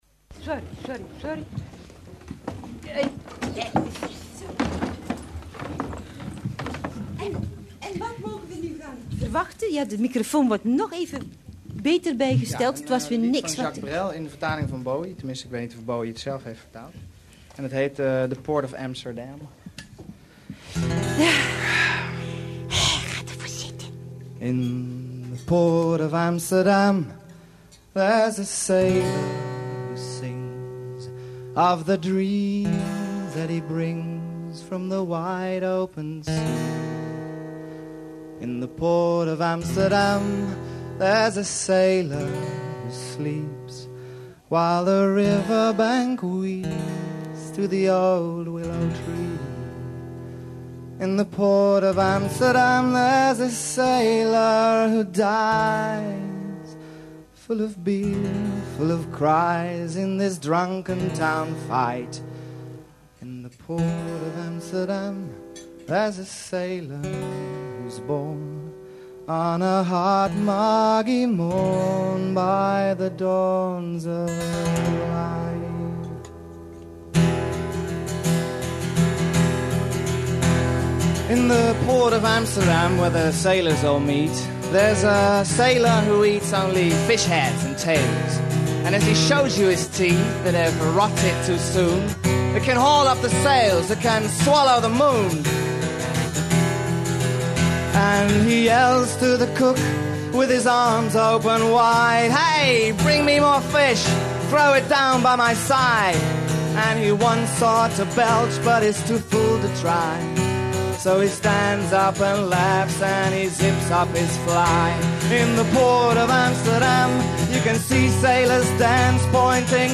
Here is a song that Anthonie Kamerling sang in 1996, in a VPRO radio show, “De Nachtzusters”: